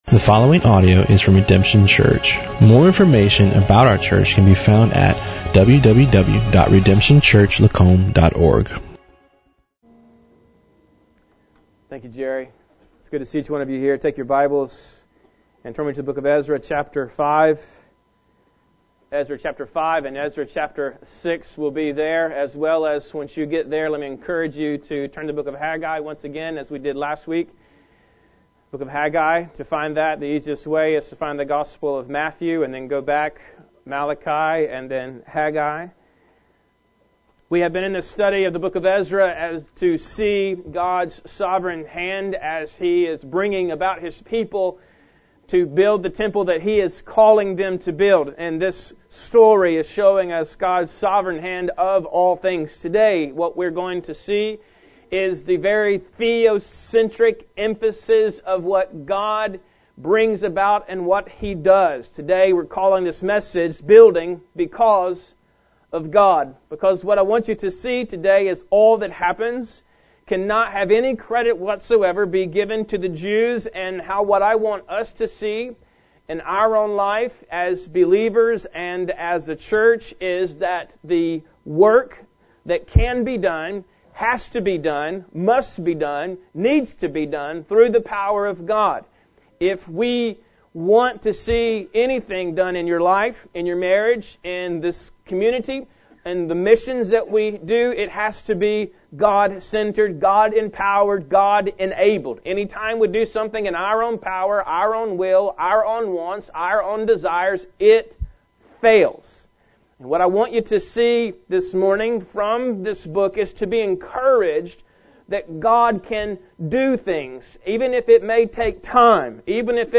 Building...Because of God - Redemption Church